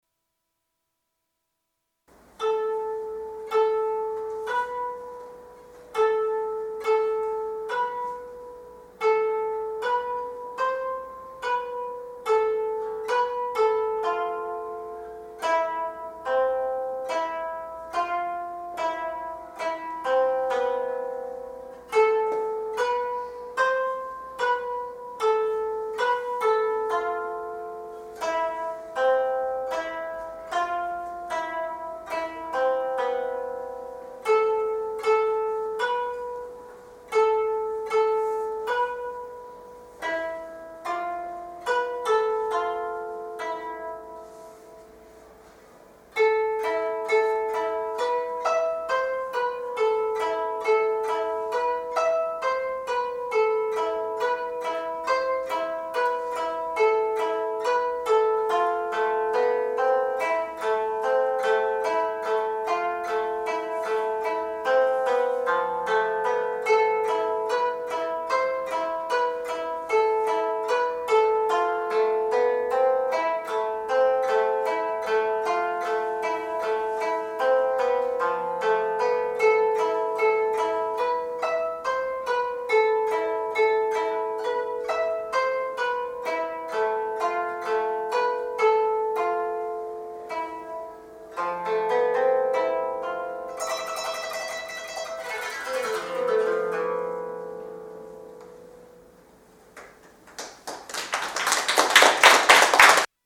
小学校邦楽鑑賞会での演奏
繰り返しの後はやや複雑な動きでお琴らしい雰囲気をだしています。